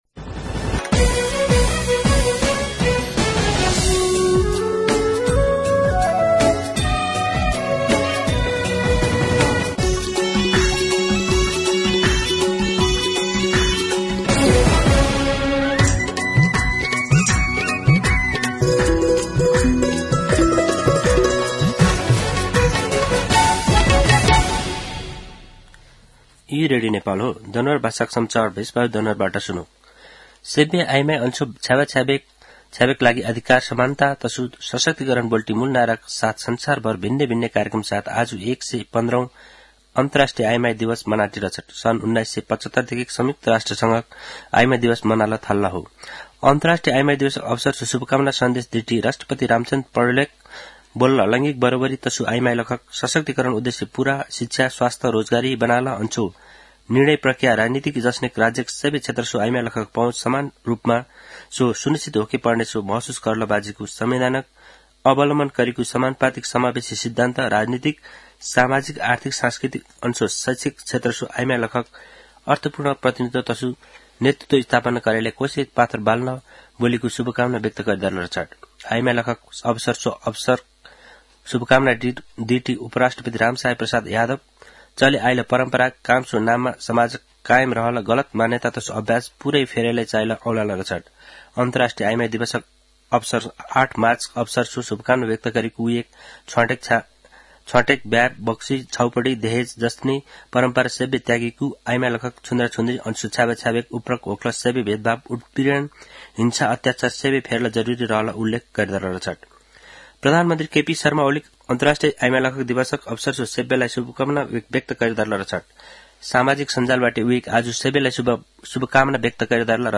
दनुवार भाषामा समाचार : २५ फागुन , २०८१
Danuwar-News.mp3